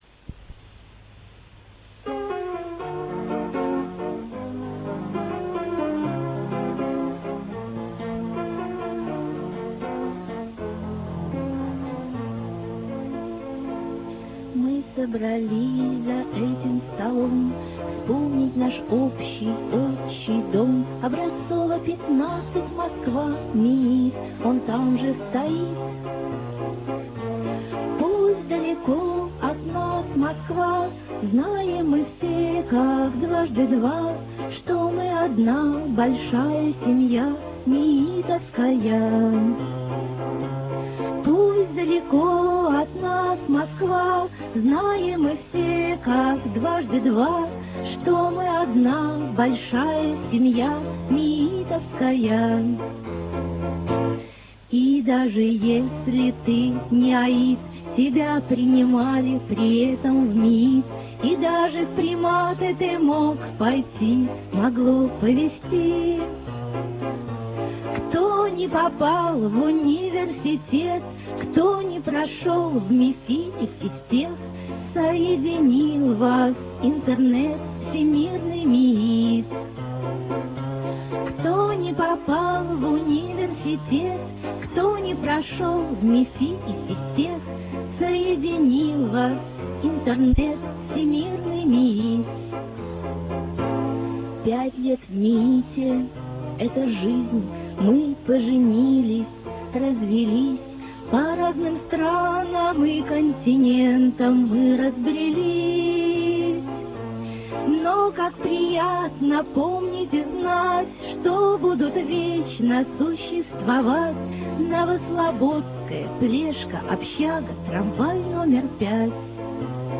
As you probably know 100th Anniversary of MIIT was celebrated in September 1996.